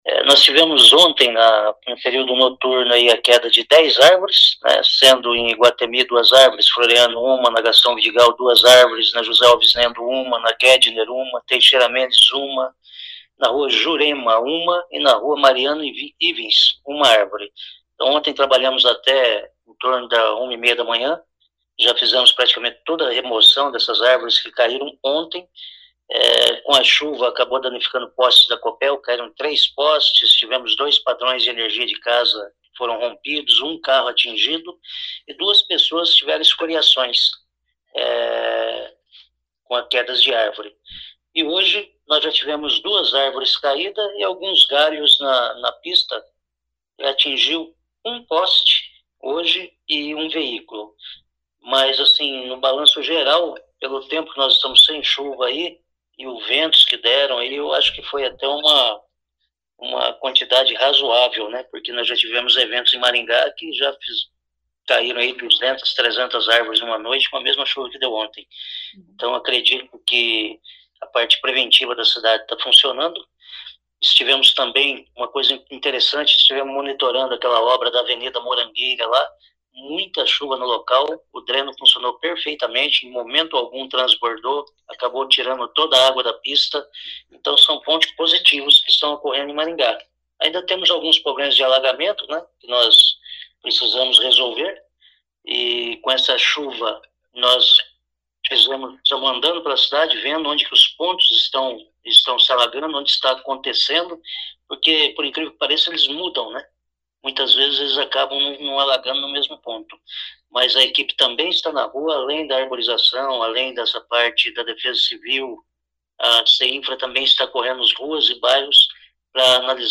O secretário faz um balanço do estrago provocado pela chuva: